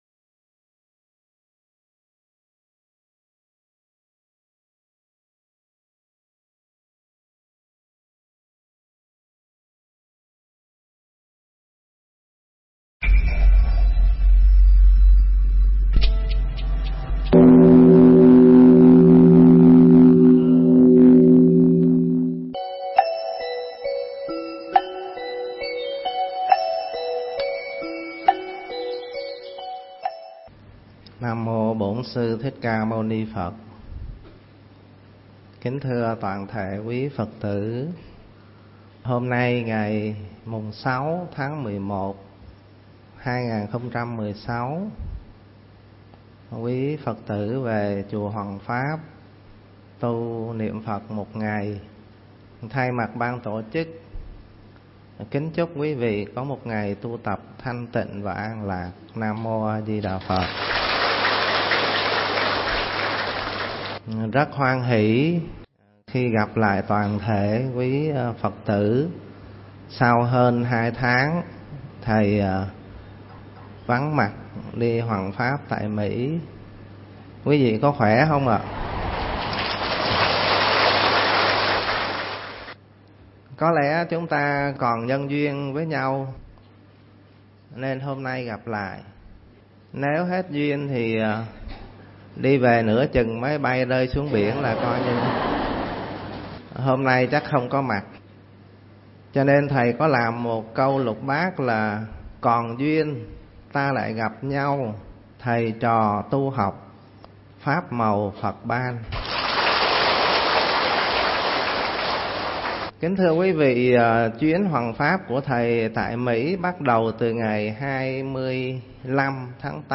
Nghe Mp3 thuyết pháp Kể chuyện hoằng pháp tại Mỹ